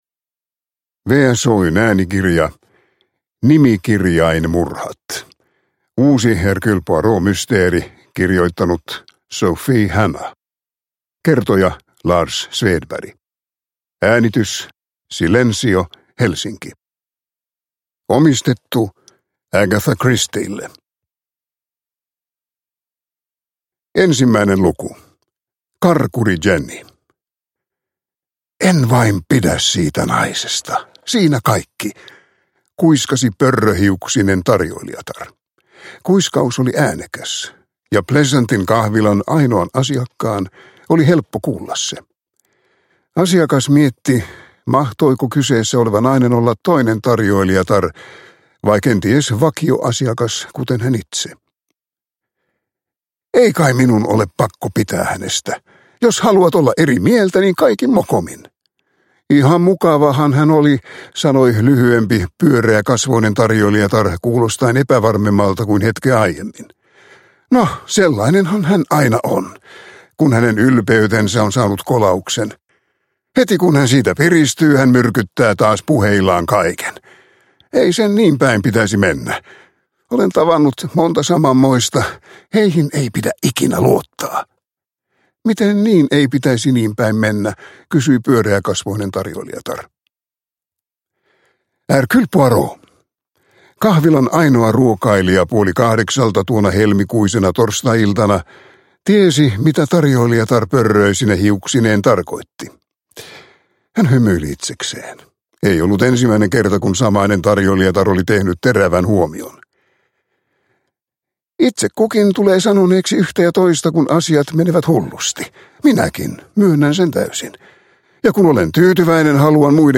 Nimikirjainmurhat – Ljudbok – Laddas ner